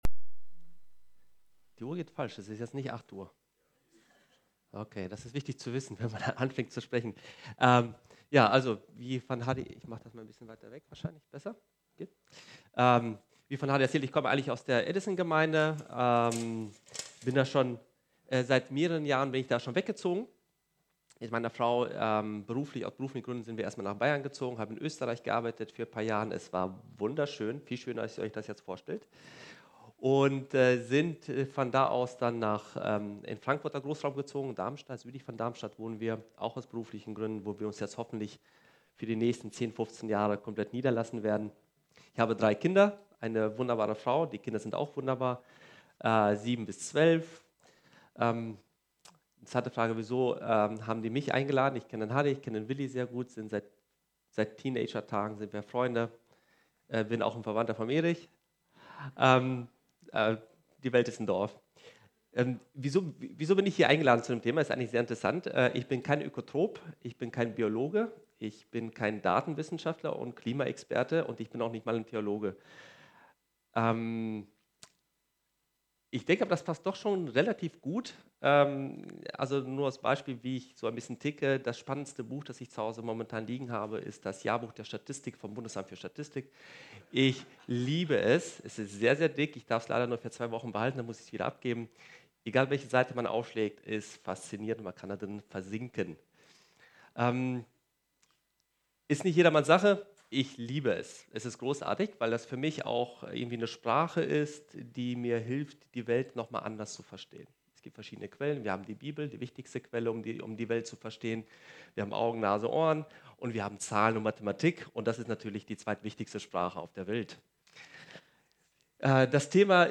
Predigt vom 10. November 2019 – efg Lage